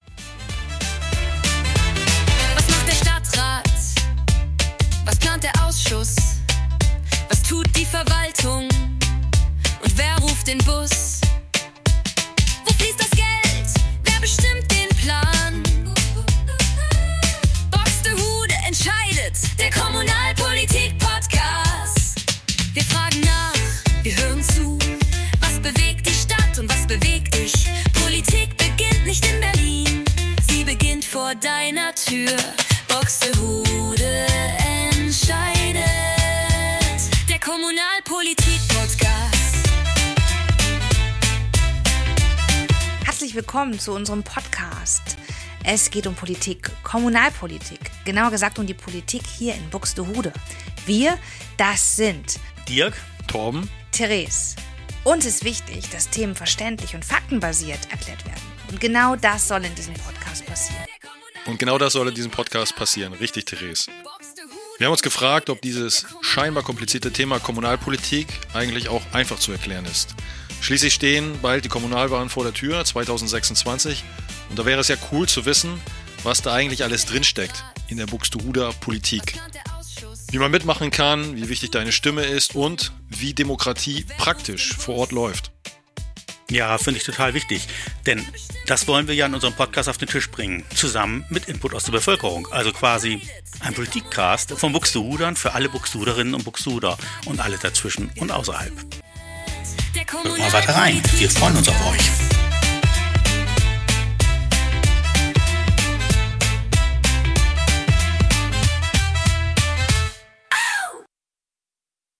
Der Jingle wurde mit Suno erstellt.